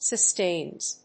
発音記号
• / sʌˈstenz(米国英語)
• / sʌˈsteɪnz(英国英語)
sustains.mp3